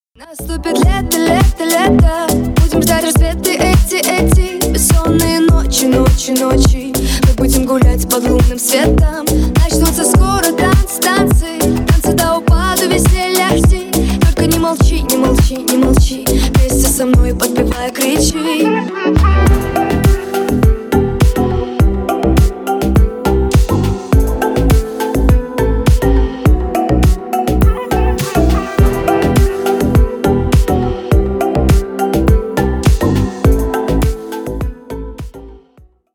• Качество: 320 kbps, Stereo
Ремикс
Поп Музыка
грустные